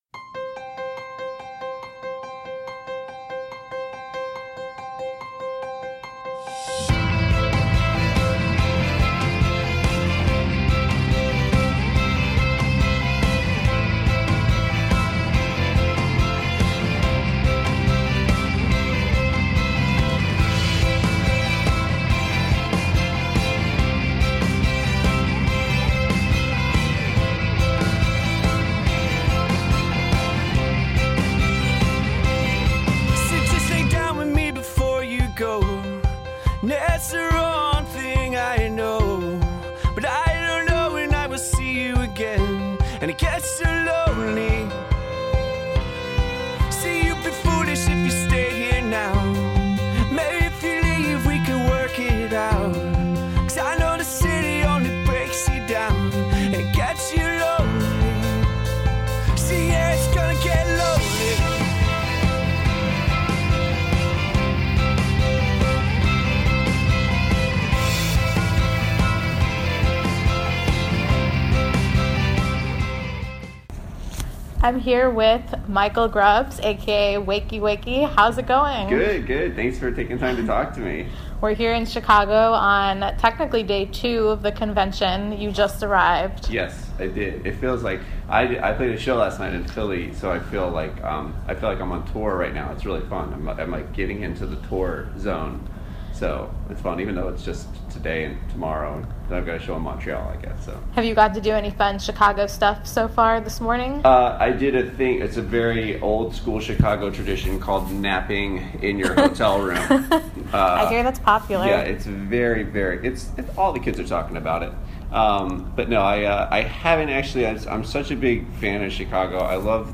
Interviews
at the One Tree Hill convention in Chicago